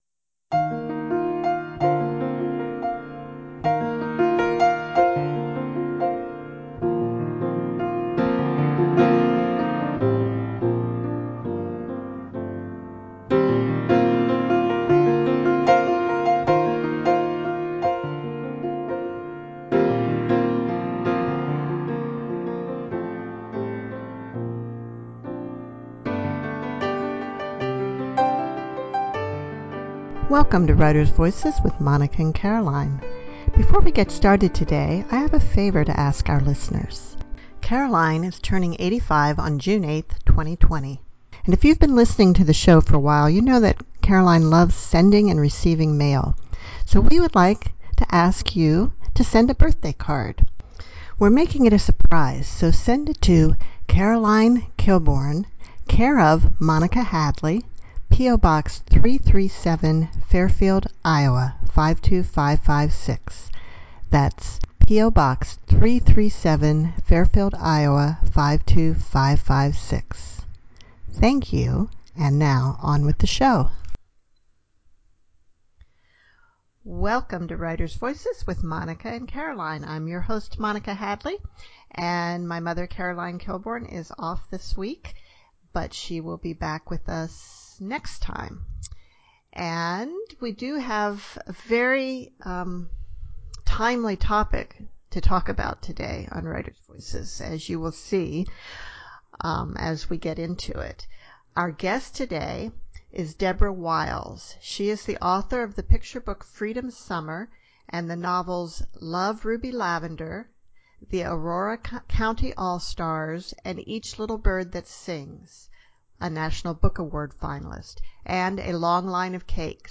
I can’t say when I’ve enjoyed an interview more.